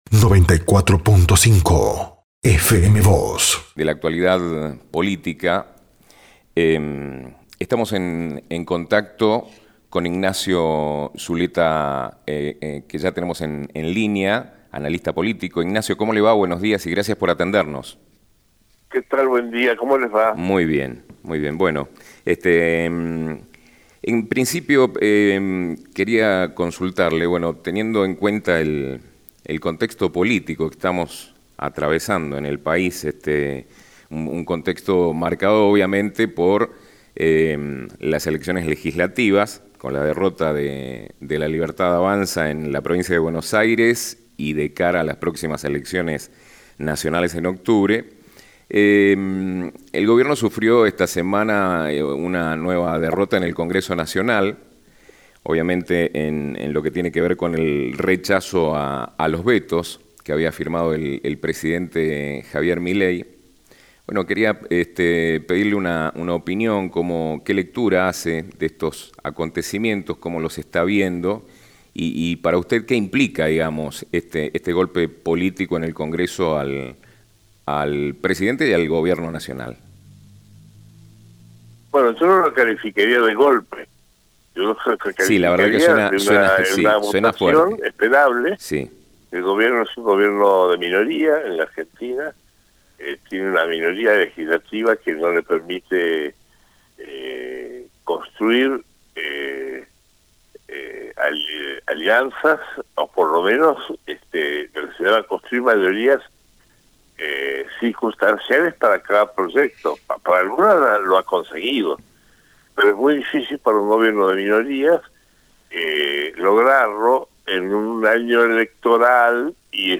Comparto un diálogo con FM Vos, 94,5 MHz de San Rafael, Mendoza. Conversamos sobre el rechazo de votos presidenciales por el Congreso.